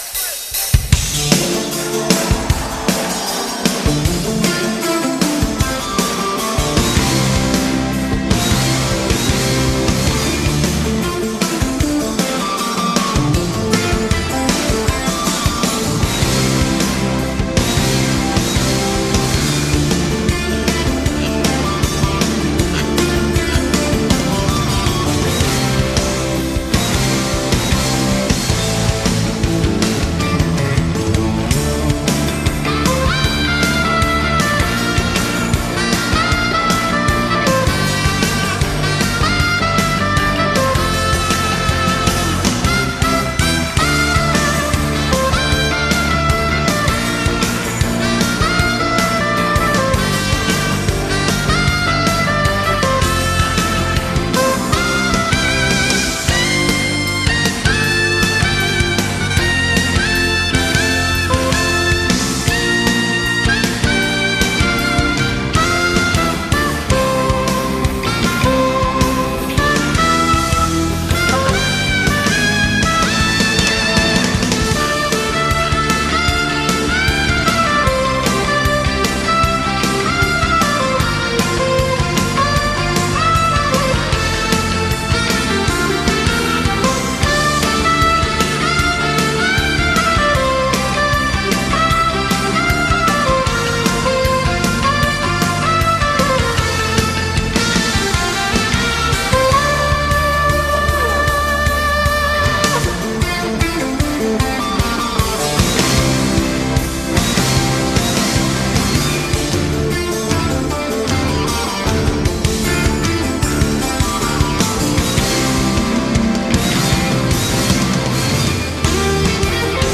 소프라노 색소폰
라이브입니다
전 멤버의 박진감과 조화가 엿보이는 파워넘치는 공연인 것 같습니다